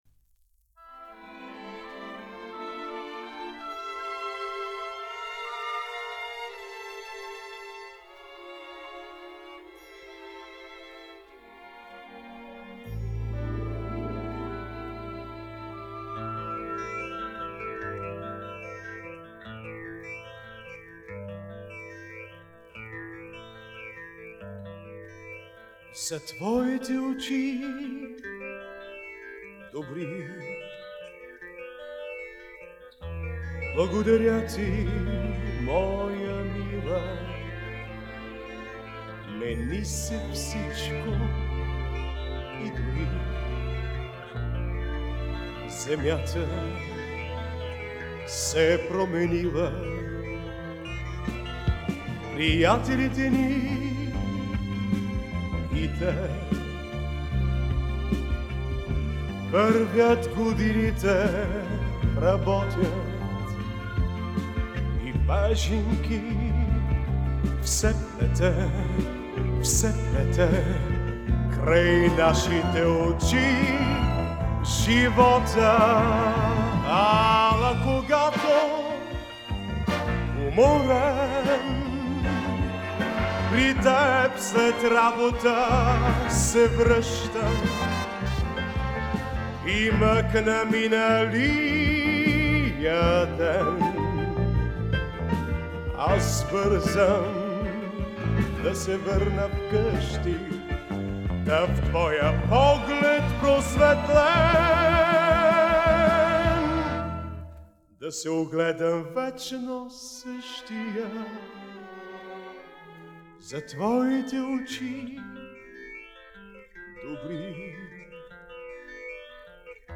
Жанр: Pop, Эстрада